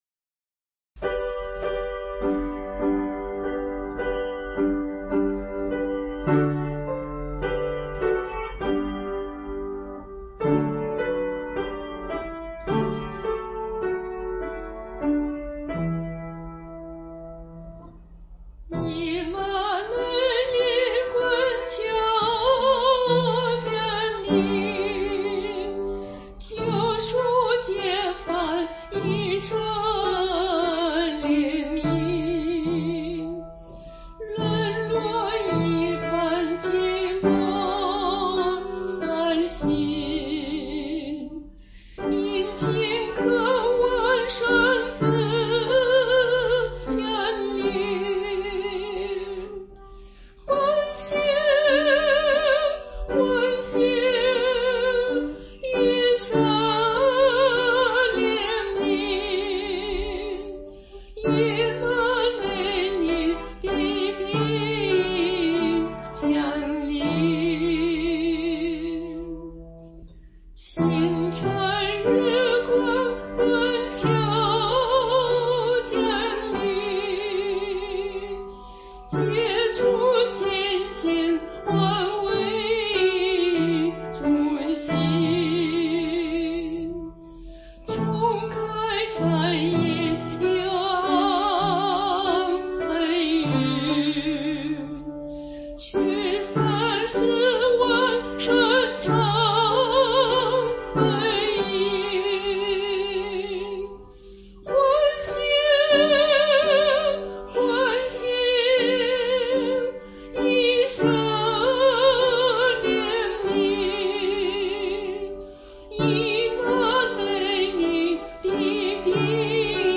伴奏
正歌按小调处理，副歌则按大调处理，结尾又用小调，把渴望和欢快的心情奇妙地结合在一起。